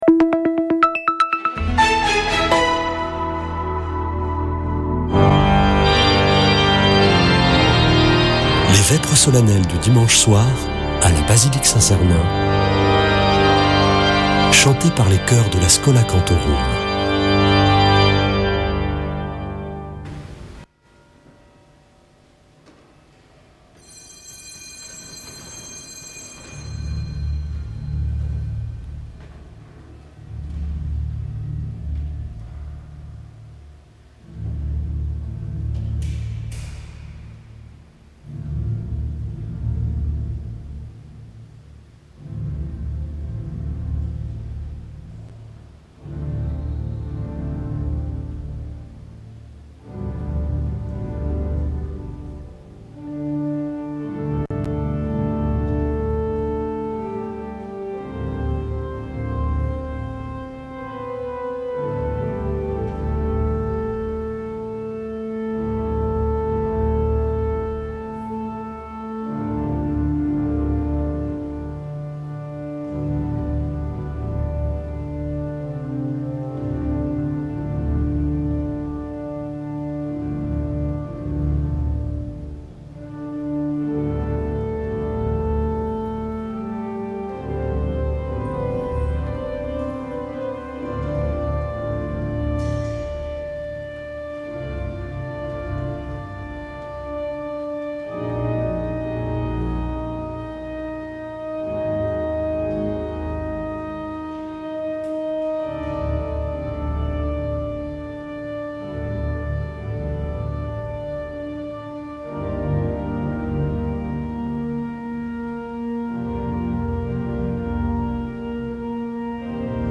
Une émission présentée par Schola Saint Sernin Chanteurs